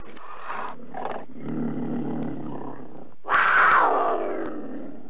جلوه های صوتی
دانلود صدای حیوانات جنگلی 42 از ساعد نیوز با لینک مستقیم و کیفیت بالا